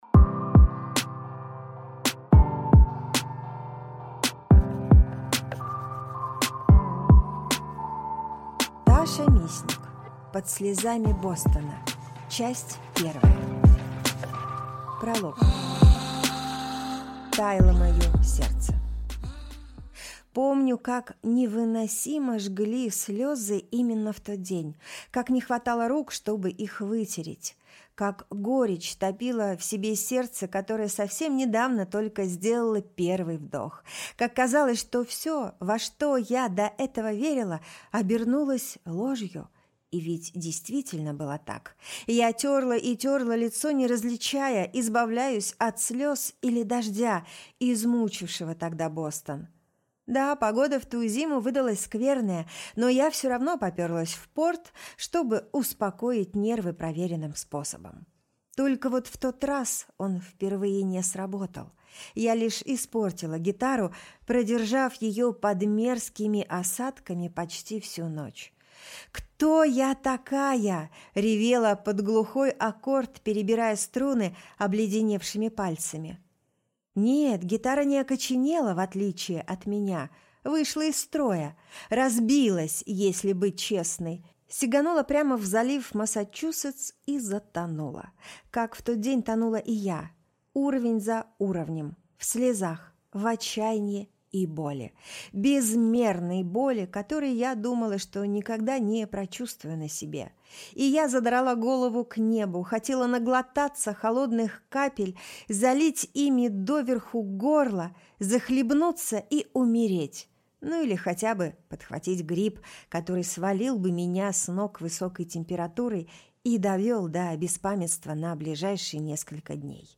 Аудиокнига Под слезами Бостона. Часть 1 | Библиотека аудиокниг